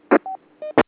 Squelch tail of a signal going into Scotts as heard by a user listening to Scotts
Bottom Right:  The squelch tail from a user getting into Scotts who is also being heard via Scotts.  It is only in this case that you will hear two beeps - like a cuckoo-clock!